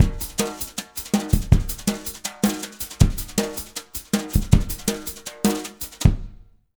Index of /90_sSampleCDs/USB Soundscan vol.08 - Jazz Latin Drumloops [AKAI] 1CD/Partition A/06-160JUNGLB
160JUNGLE7-R.wav